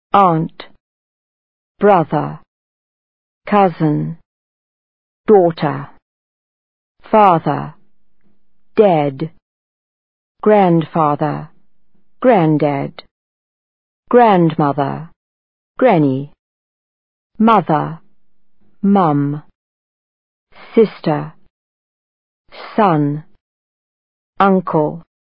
Pronunciation: Relatives
Este archivo de sonido contiene la pronunciación de las palabras en inglés que sirven para denominar distintos miembros de una familia: mother, father, son, daughter, sister, brother, uncle, aunt, cousin, grandfather y grandmother.
Permite escuchar la correcta pronunciación de las palabras en inglés que denominan miembros de una familia (aunt, sister, father, etc.).